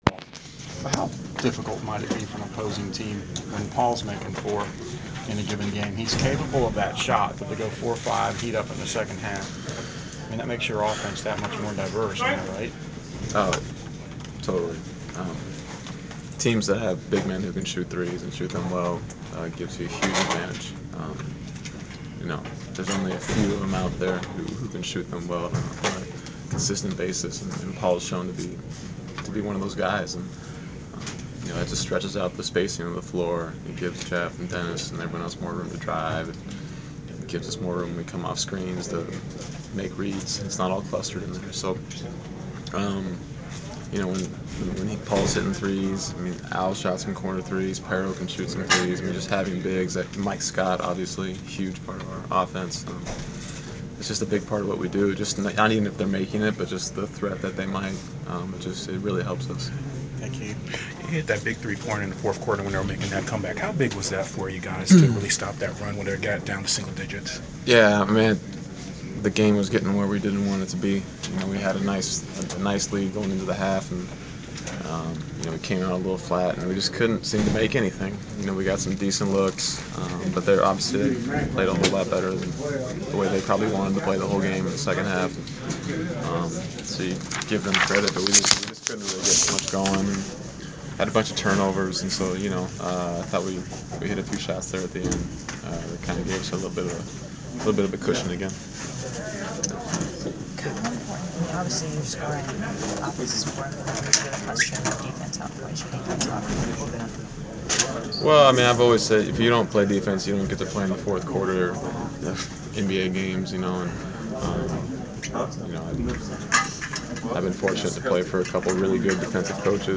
Inside the Inquirer: Postgame interview with Atlanta Hawks’ Kyle Korver (12/7/14)
We caught up with Atlanta Hawks’ guard Kyle Korver following the team’s 96-84 home win over the Denver Nuggets on Dec. 7.